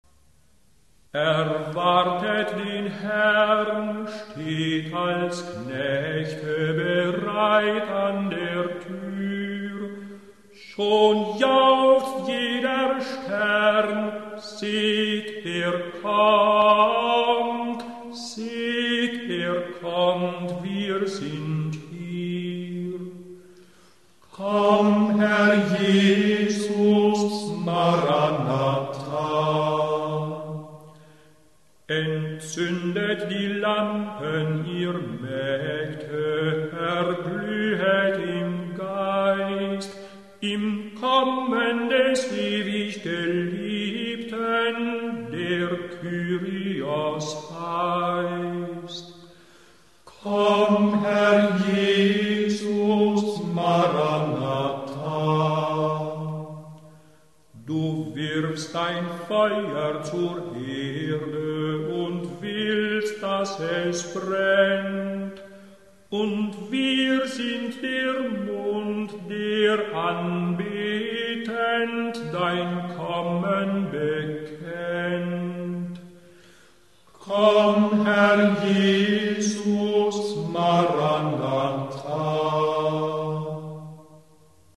Hymnen zur Lesehore